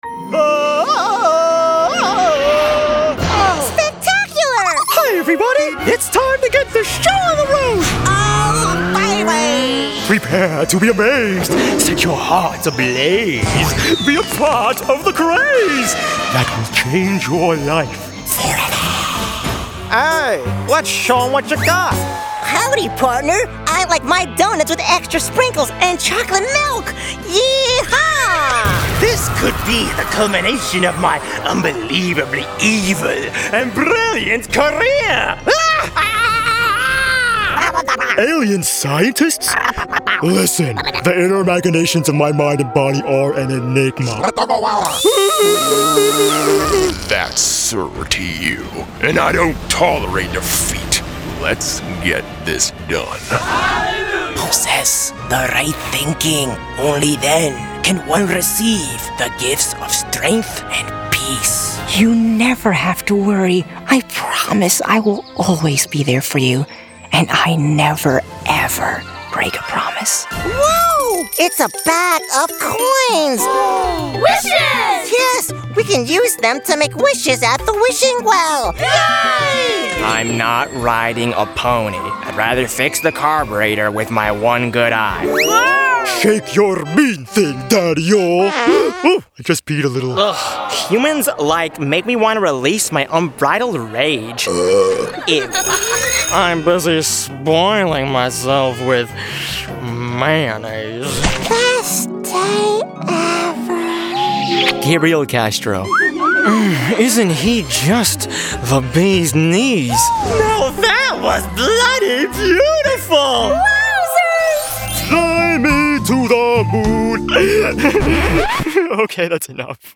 Teenager, Young Adult, Adult
Has Own Studio
ANIMATION 🎬